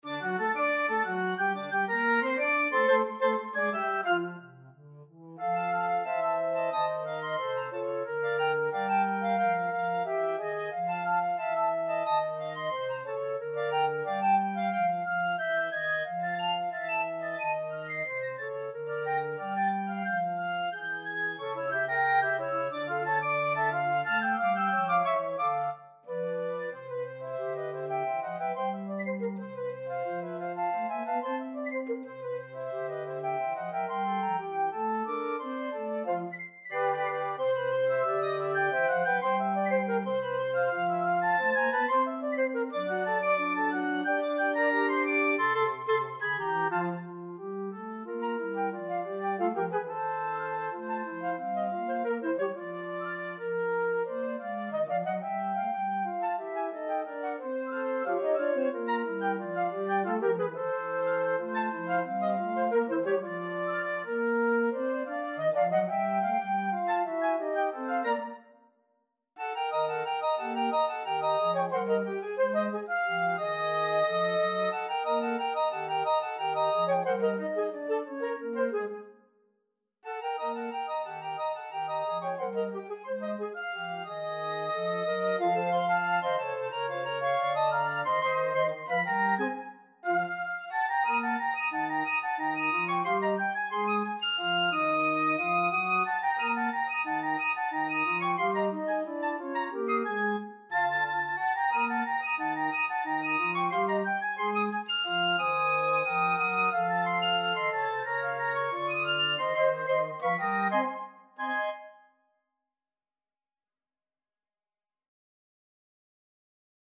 Step back into the dazzling days of ragtime
” a vibrant work of syncopation for flute choir.